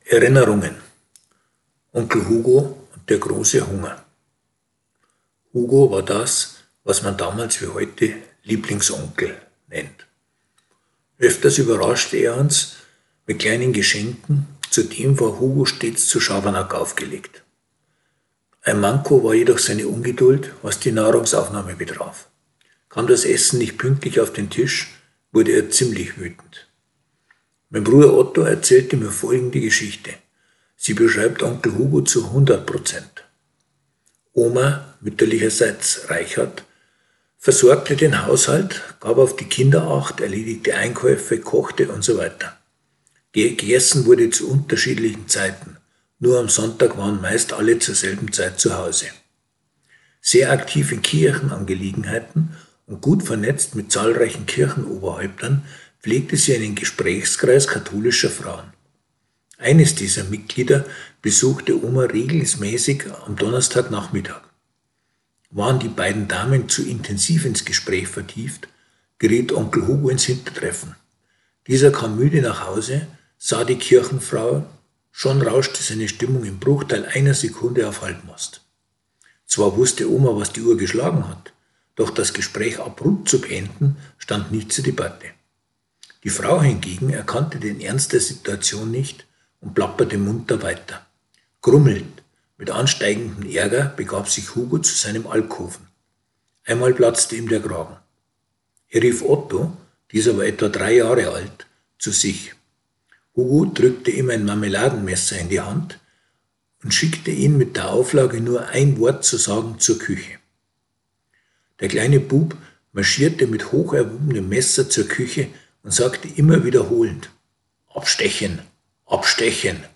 Beitrag vorlesen (2:19 Minuten)